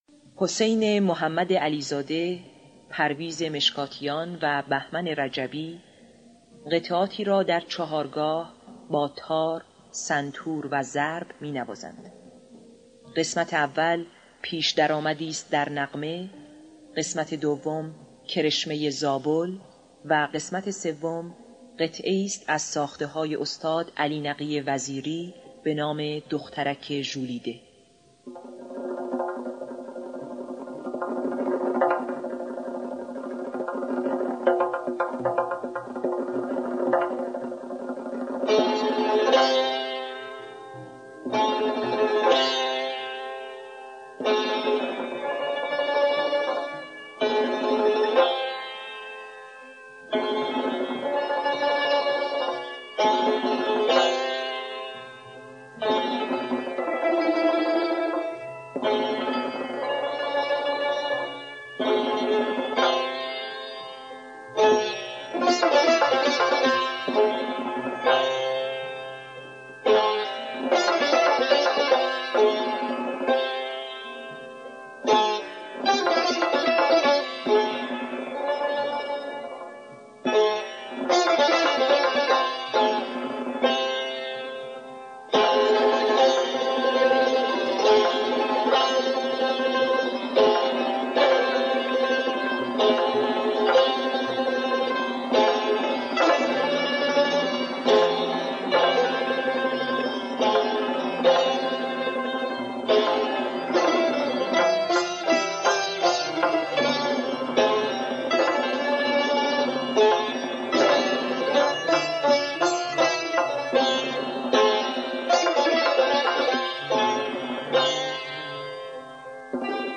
تار
سنتور
تنبک